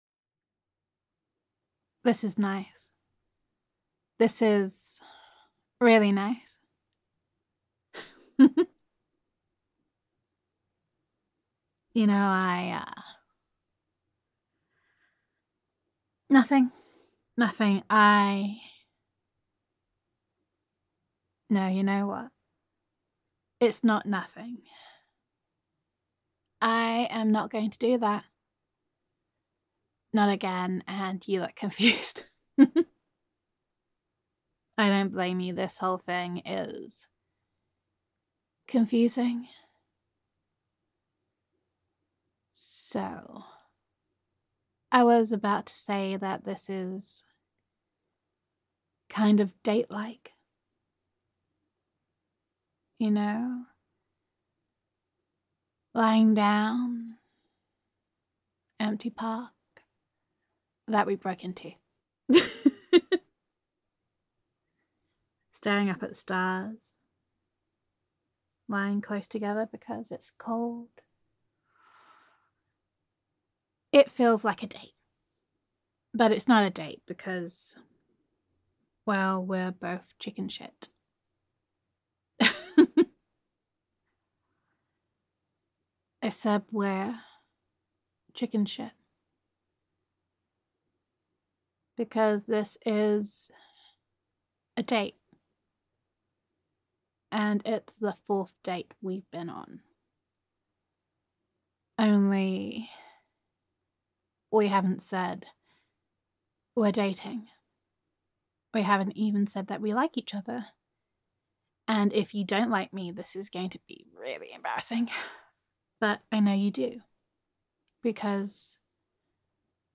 [F4A] Ninja Dating
[Best Friend Roleplay]